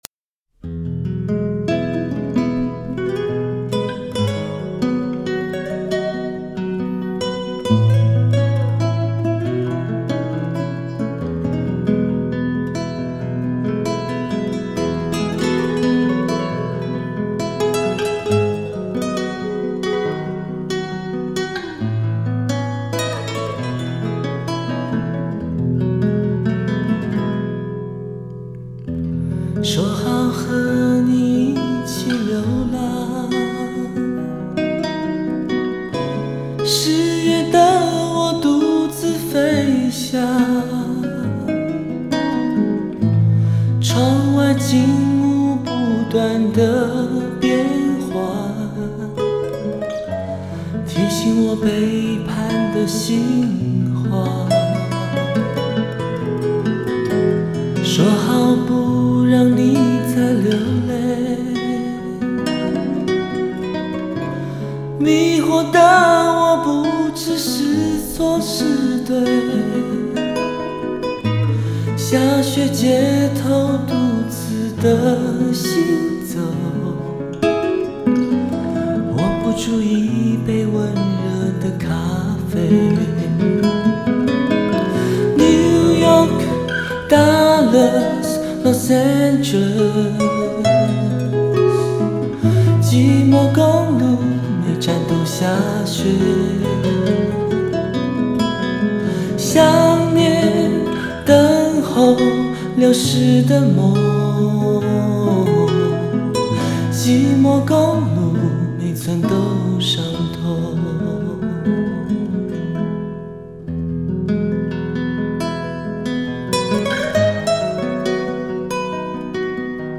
电音 收藏 下载